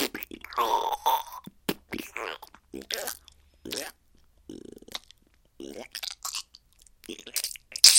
描述：Beatbox创意声音/循环4 bar 120bpm 付出就有回报功不可没。
Tag: 创意 敢-19 循环 口技